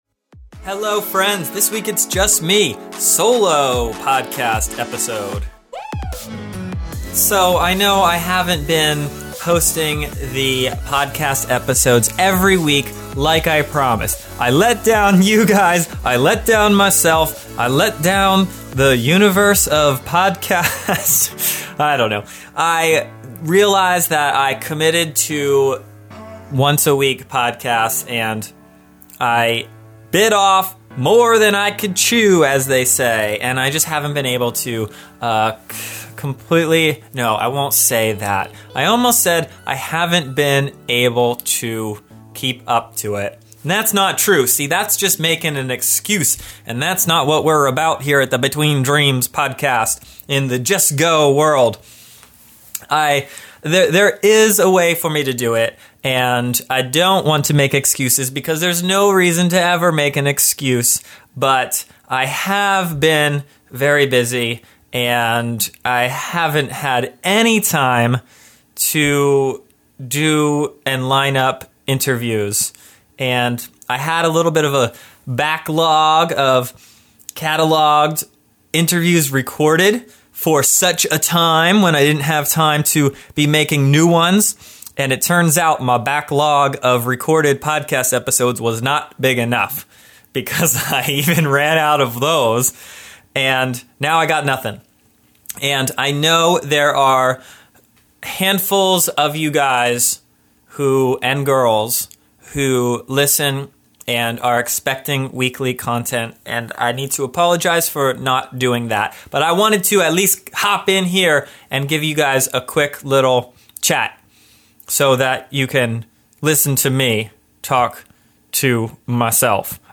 Solo round, no guest. My thoughts on getting out and making things happen.